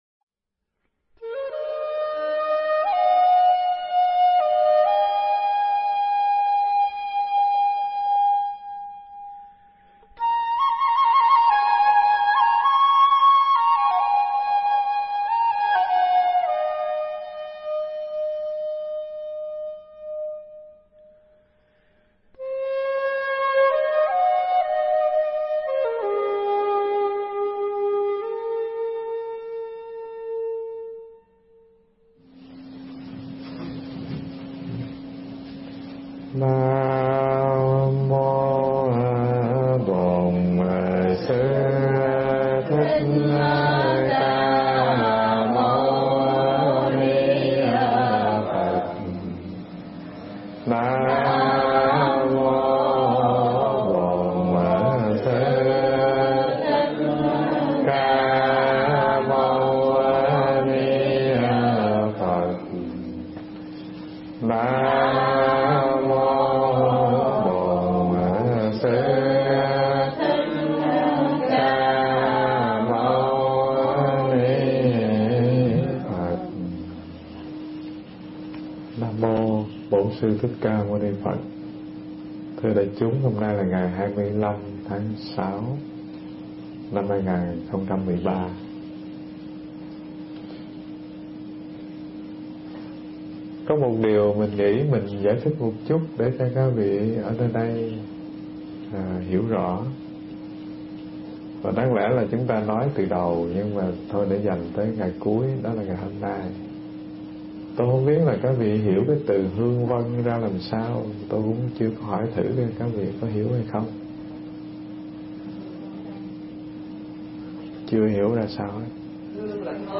Nghe Mp3 thuyết pháp Niệm Thân Hành Phần 8